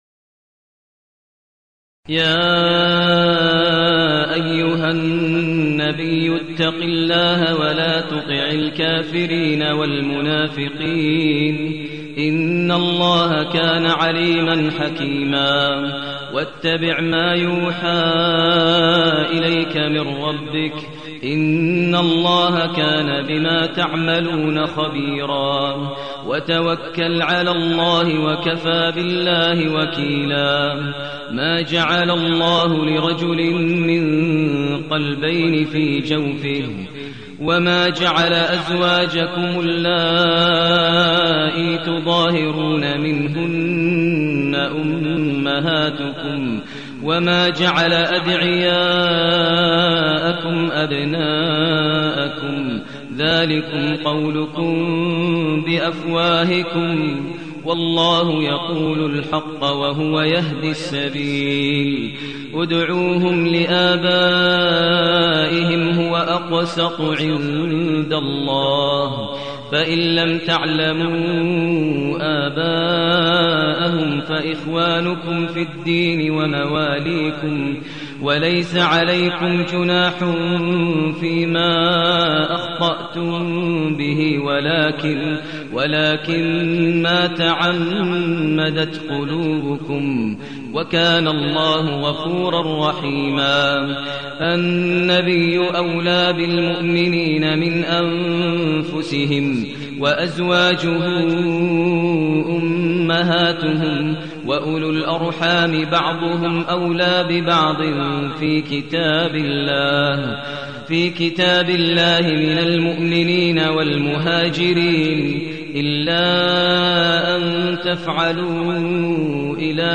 المكان: المسجد الحرام الشيخ: فضيلة الشيخ ماهر المعيقلي فضيلة الشيخ ماهر المعيقلي الأحزاب